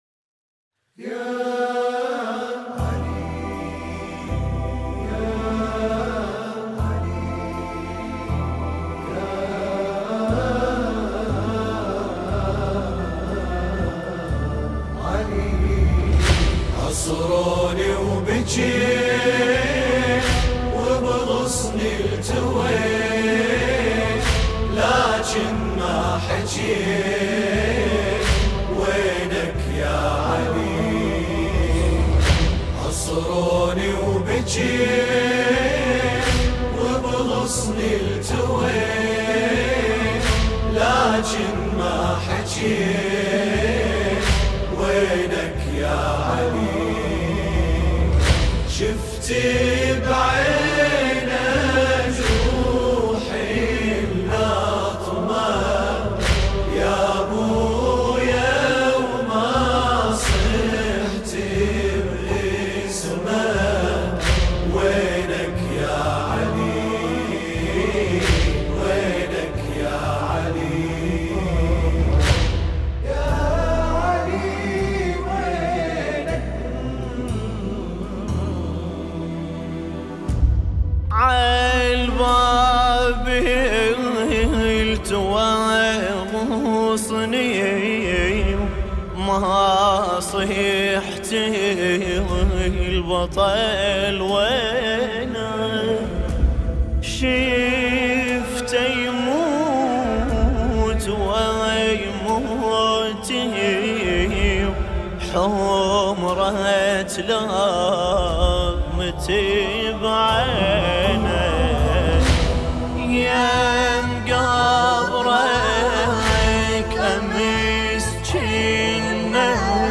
أداء و ألحان